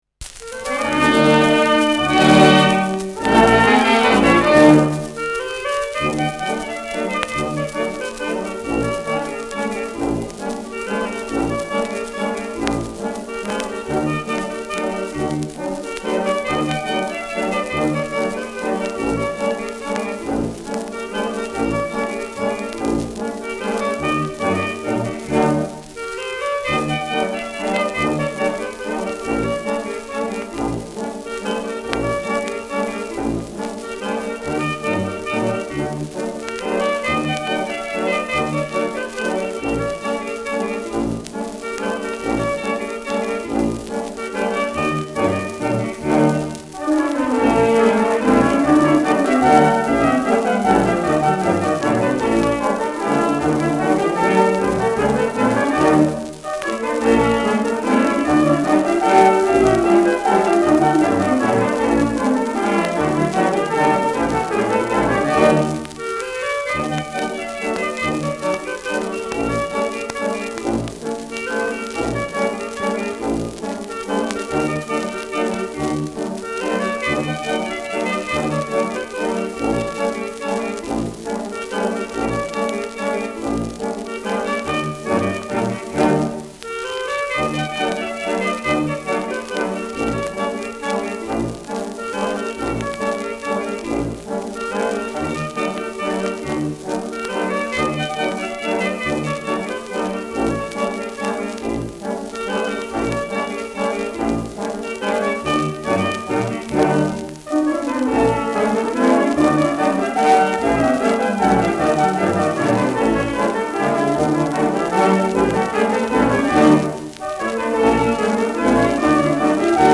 Schellackplatte
präsentes Knistern : leichtes Rauschen : gelegentliches Knacken
Kapelle Almenrausch, München (Interpretation)
[München] (Aufnahmeort)